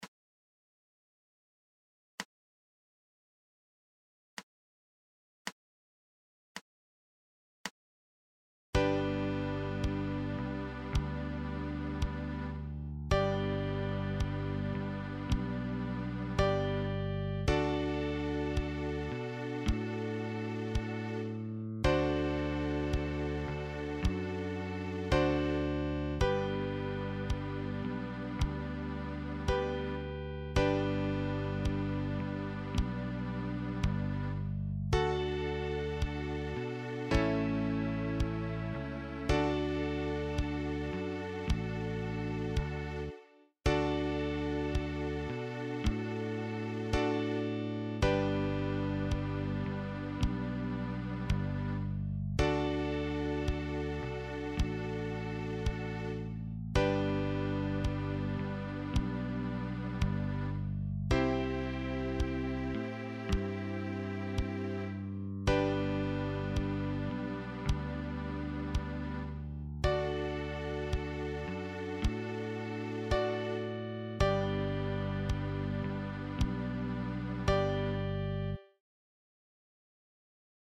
Liederbuch für die chromatische Mundharmonika.
Soundbeispiel – Melodie & Band sowie Band alleine: